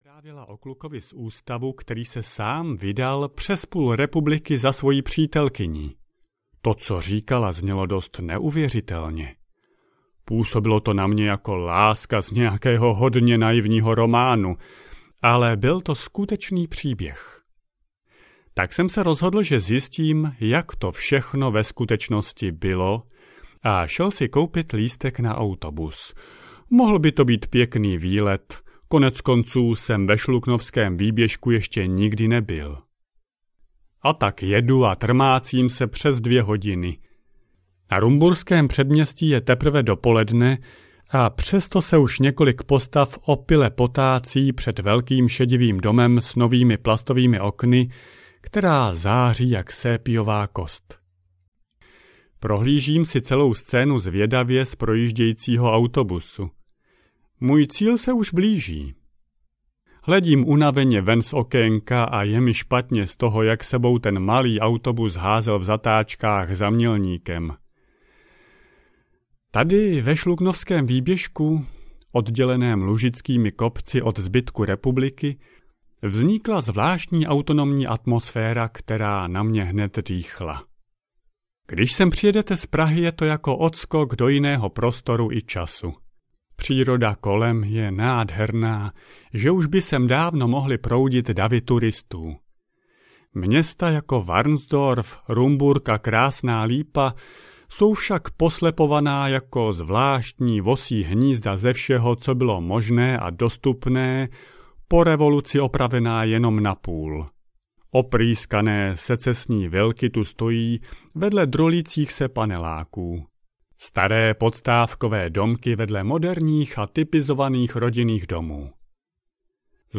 Útěk na koloběžce audiokniha
Ukázka z knihy